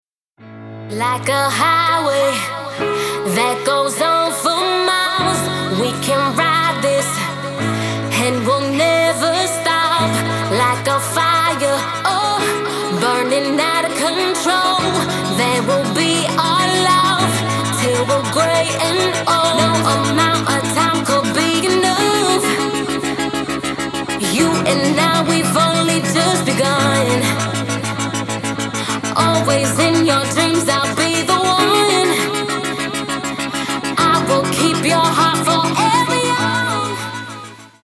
• Качество: 224, Stereo
поп
женский вокал
dance
vocal